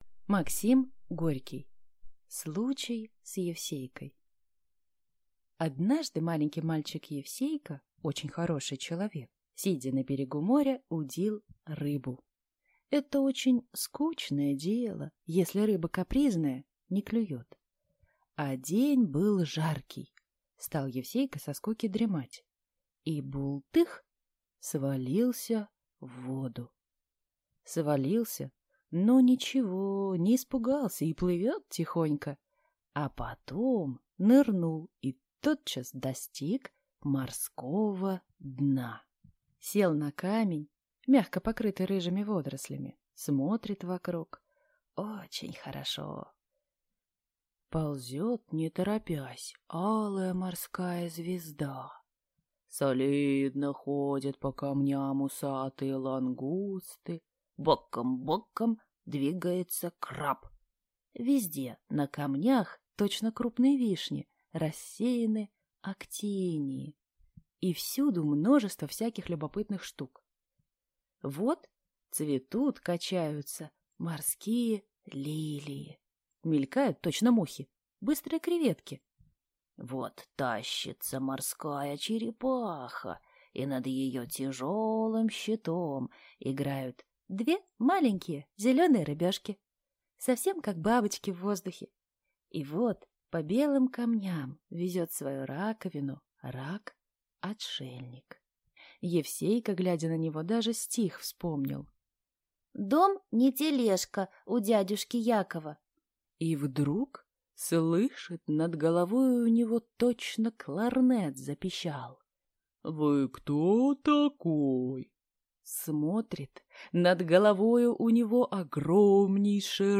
Аудиокнига Случай с Евсейкой | Библиотека аудиокниг